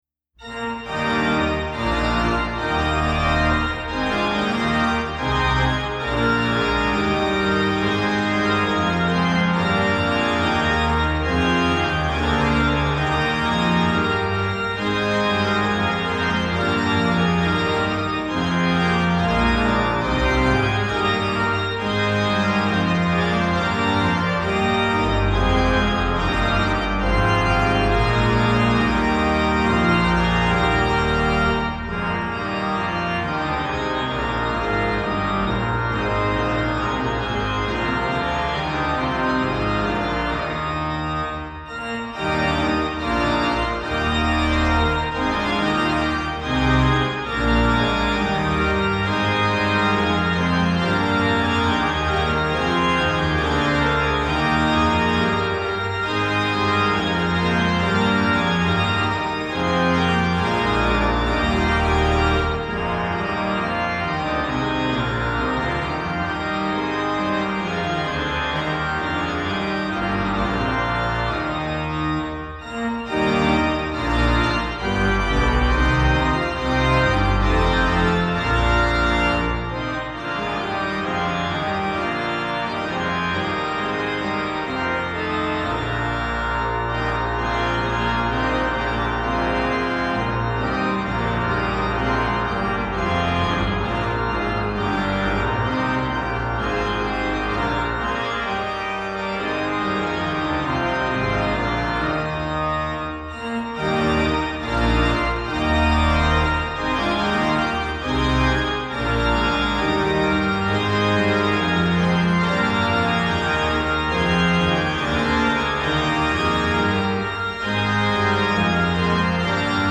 Organ Music for Easter
Voicing: Organ